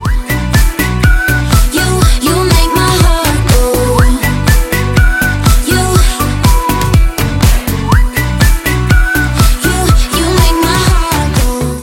• Качество: 320, Stereo
свист
легкие
Простая мелодия